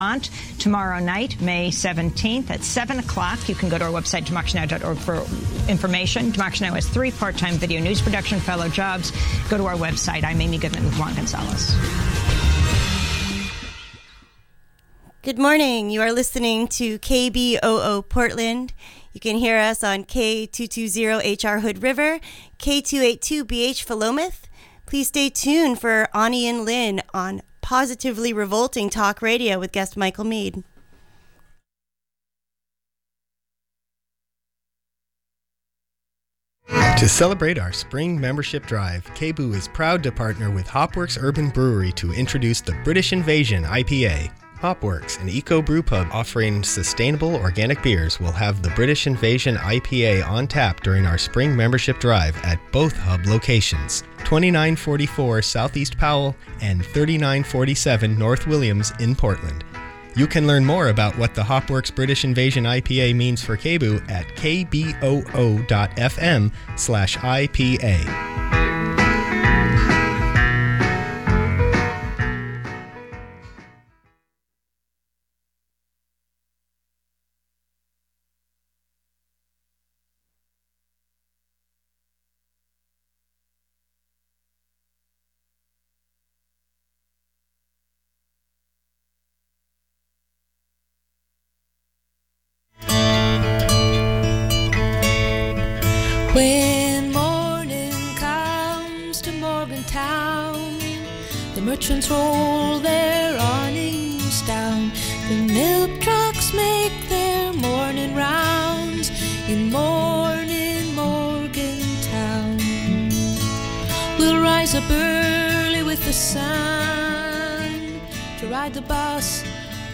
live in the studio to take your calls